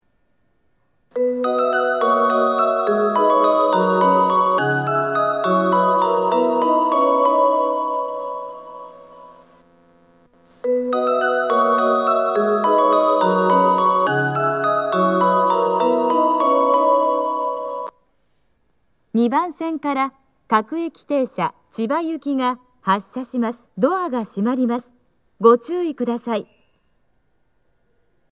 早朝時間帯は自動放送が流れず、発車メロディーのみ流れます。
発車メロディー 時間がある列車なら2コーラス目も十分狙えます。